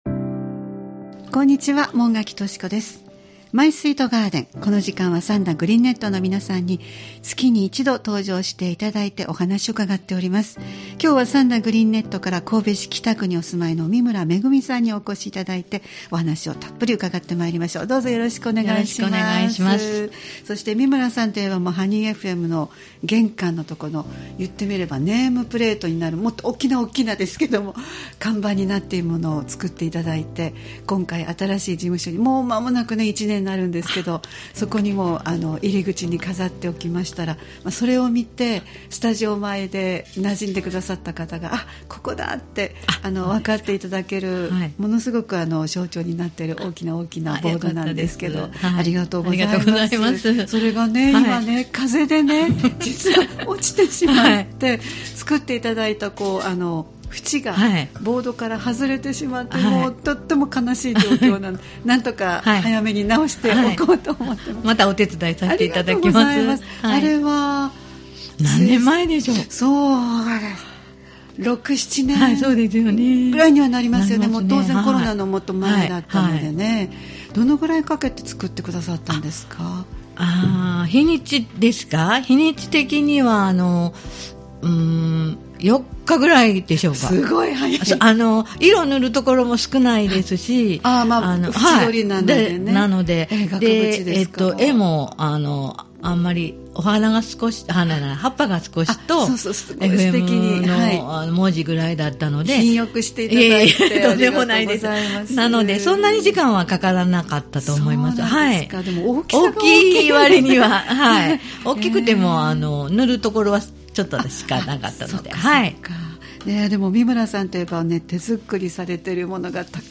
毎月第1火曜日は兵庫県三田市、神戸市北区、西宮市北部でオープンガーデンを開催されている三田グリーンネットの会員の方をスタジオにお迎えしてお庭の様子をお聞きする「マイスイートガーデン」をポッドキャスト配信しています（再生ボタン▶を押すと番組が始まります）